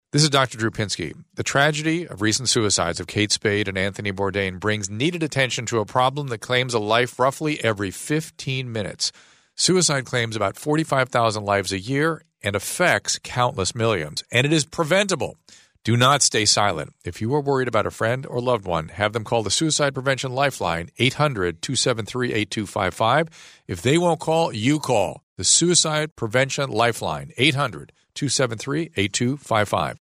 Dr. Drew PSA